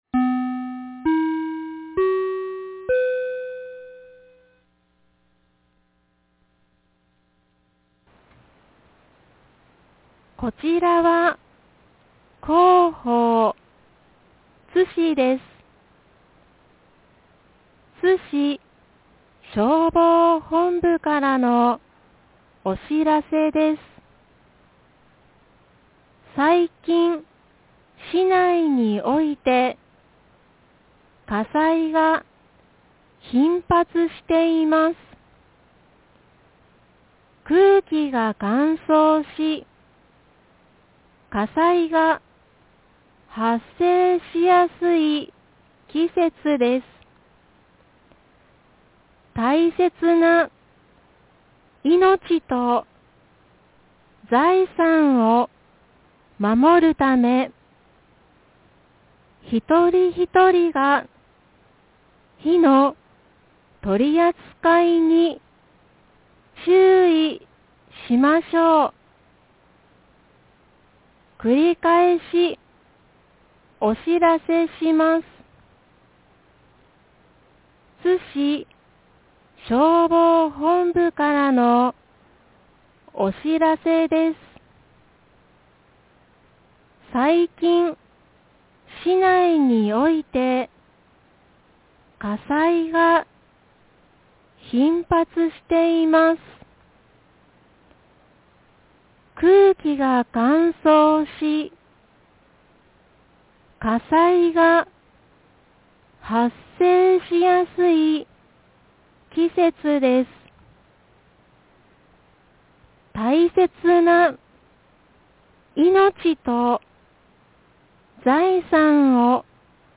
2024年12月15日 12時59分に、津市より高野尾、豊が丘、大里、白塚、栗真、一身田、北立誠、南立誠、津西、安東、櫛形、片田、神戸、新町、養正、敬和、育生、修成、藤水、南が丘、高茶屋、雲出、誠之、成美、立成、桃園、戸木、栗葉、榊原、豊津、上野、黒田、千里ヶ丘、椋本、明、安西、雲林院、河内、高宮、長野、辰水、草生、村主、安濃、明合、香良洲、大井、波瀬、川合、高岡、家城、川口、大三、倭、八ツ山、竹原、八知、太郎生、伊勢地、八幡、多気、下之川へ放送がありました。
放送音声